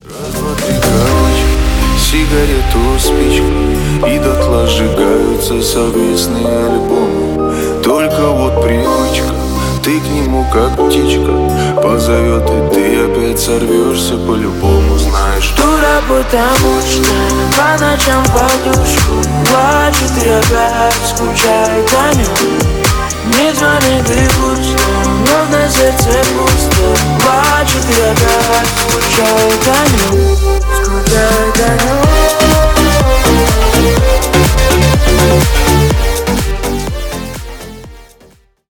Ремикс # грустные # спокойные